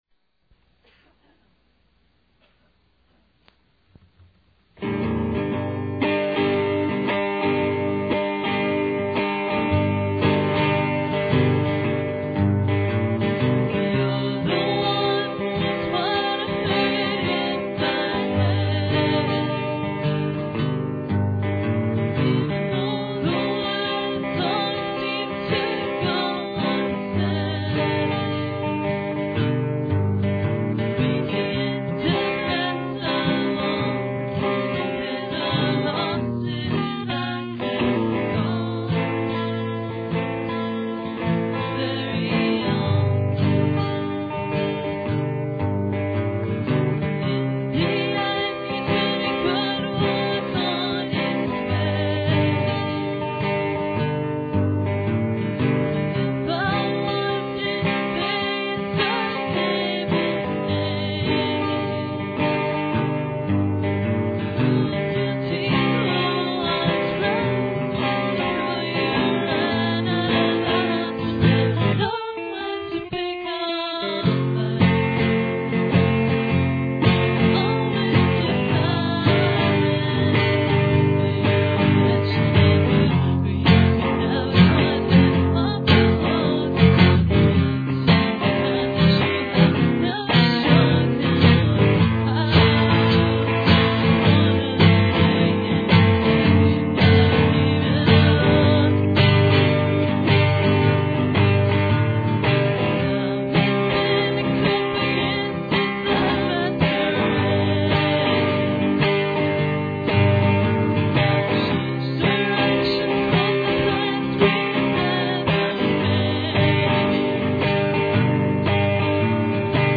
Painstakingly recorded to normal bias generic brand audiotape